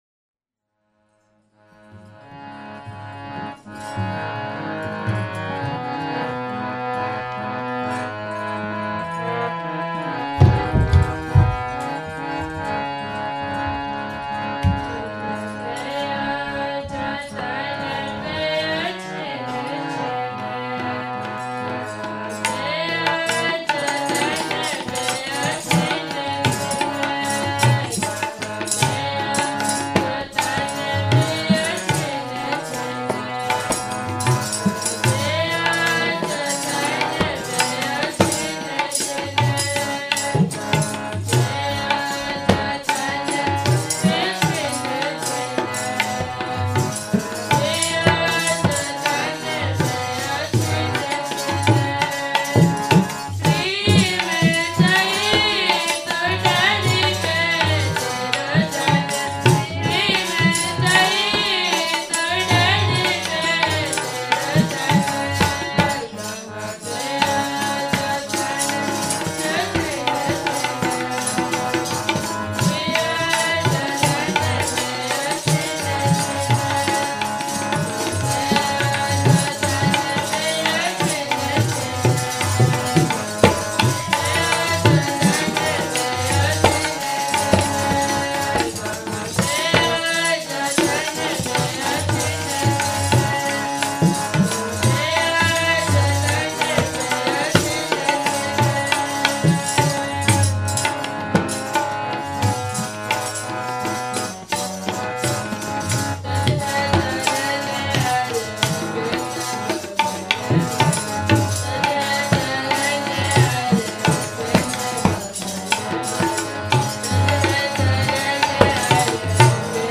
audio / Keertan /